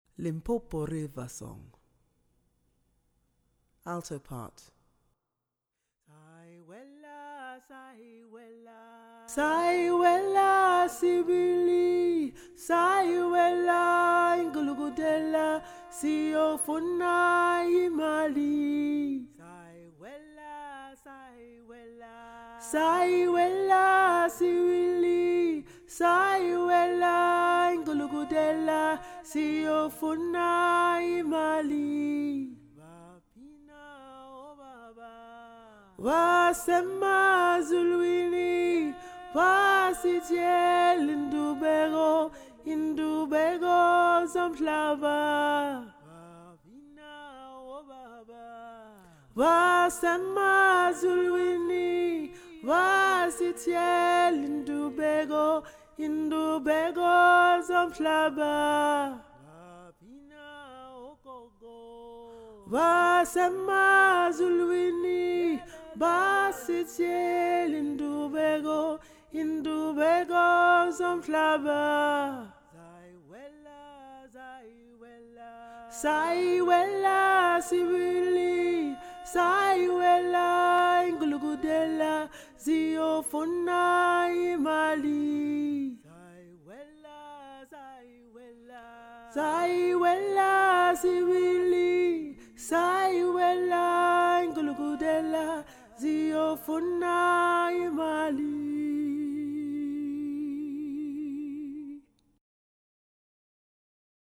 Limpopo-River-Song-Alto.mp3